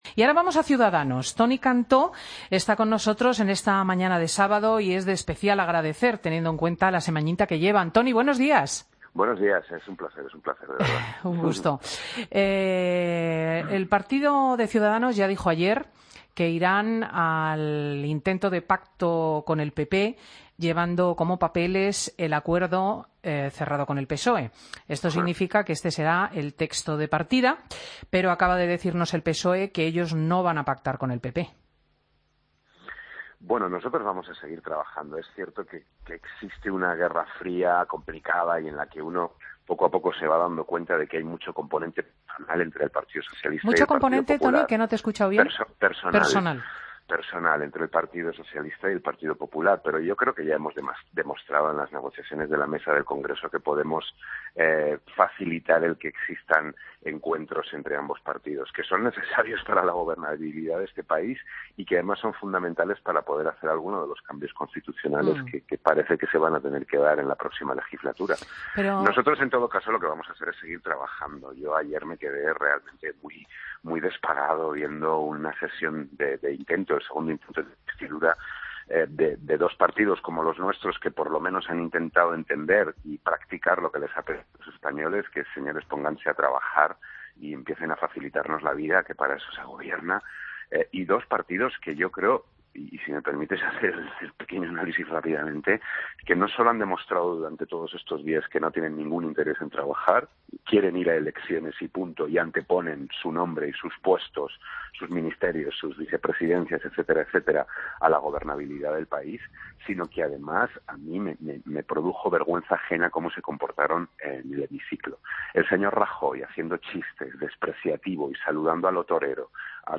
Escucha la entrevista a Toni Cantó, Diputado de Ciudadanos, en Fin de Semana COPE